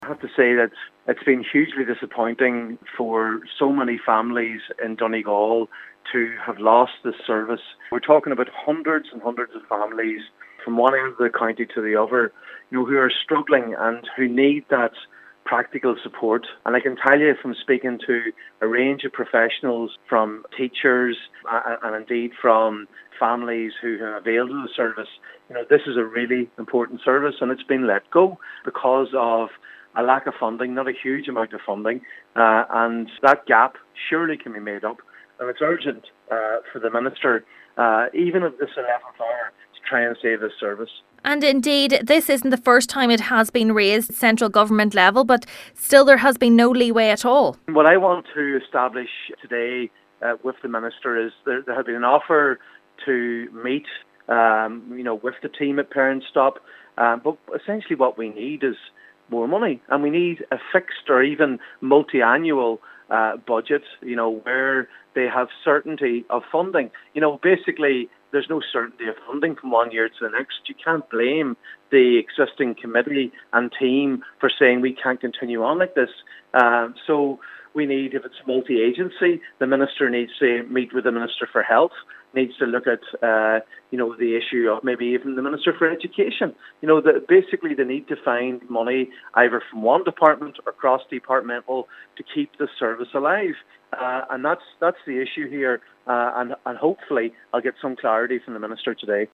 Senator MacLochlainn is hoping more clarity on funding can be provided: